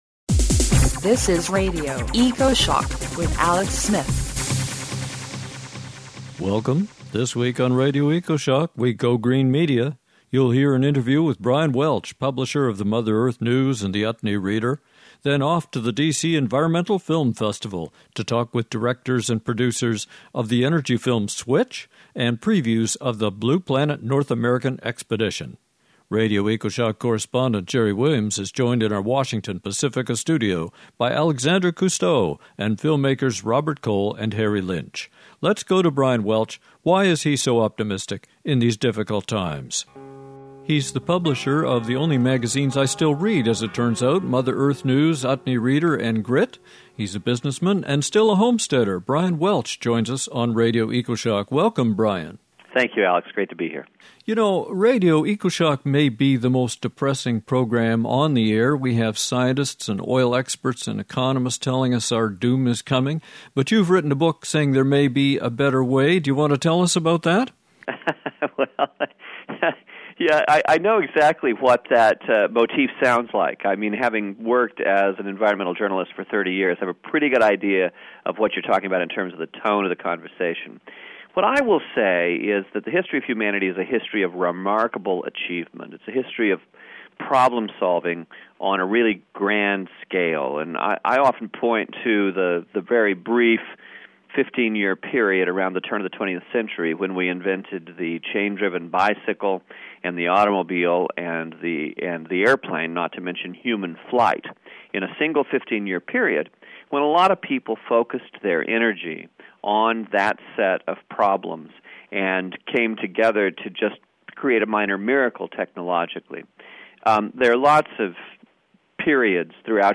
But do listen to this radio interview for the innovative new ideas for communication, including the ability to assemble your own film on the Net, something we all should aim for.